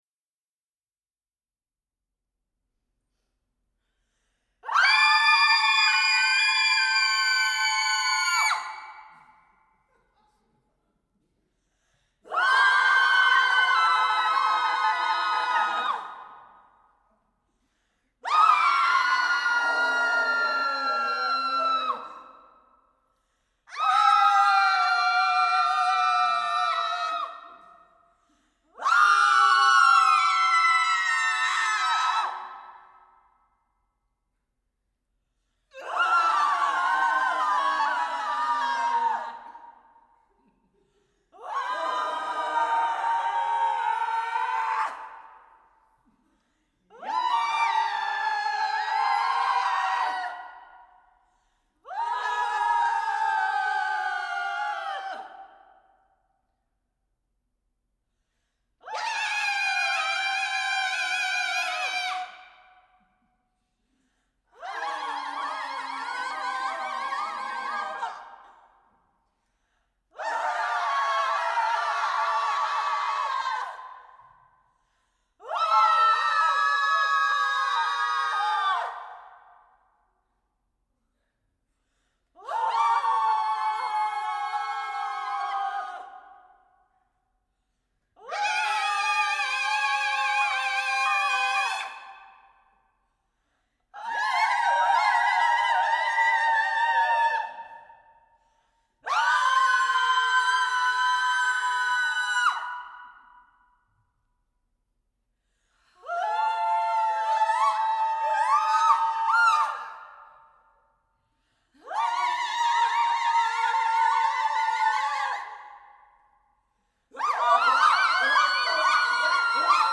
sopranos